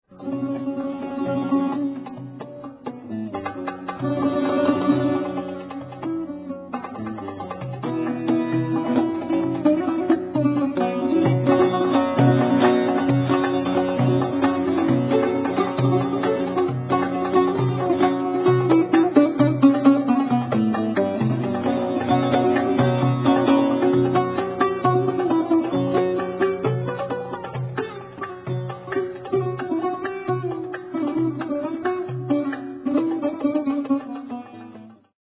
recorded live in concert
nay, 'ud, buzuq, and bowed-tanbur